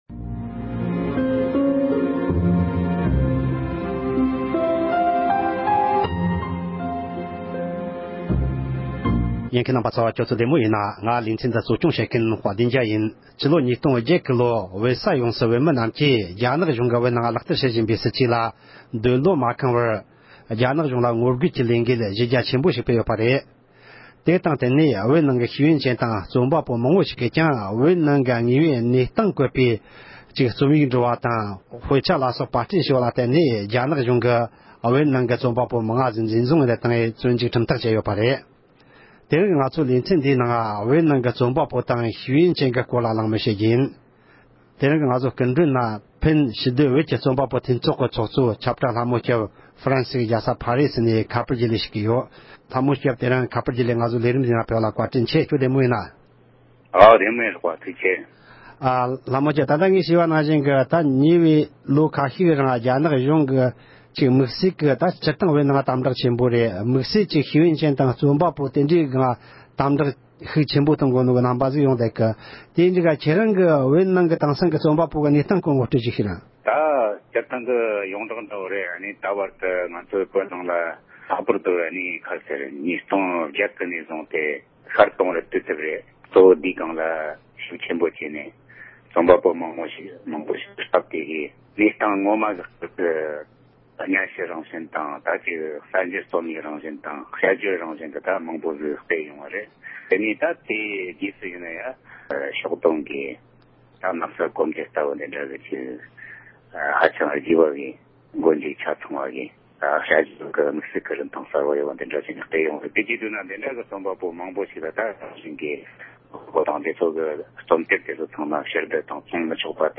རྒྱ་ནག་གཞུང་གིས་བོད་ནང་རྩོམ་པ་པོ་དང་ཤེས་ཡོན་མི་སྣ་འཛིན་བཟུང་བཙོན་འཇུག་བྱས་དང་བྱེད་བཞིན་པའི་ཐད་གླེང་མོལ།